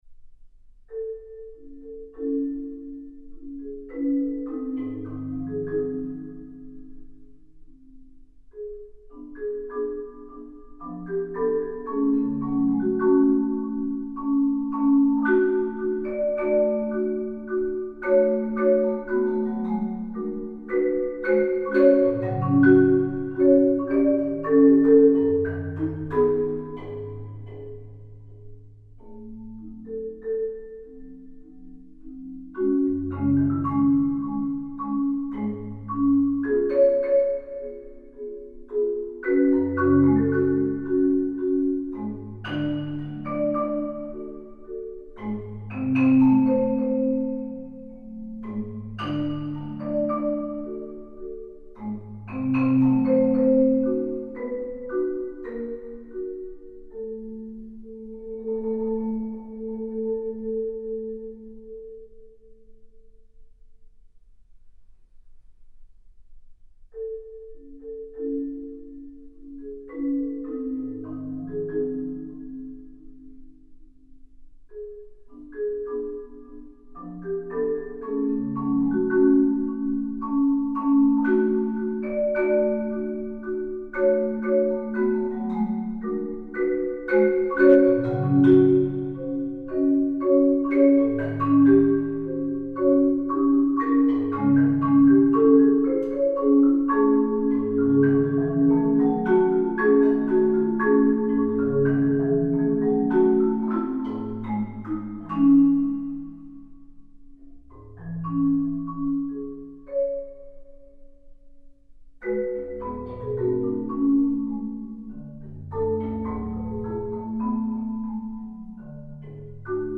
Genre: Solo Marimba (4-mallet)
Marimba (5-octave)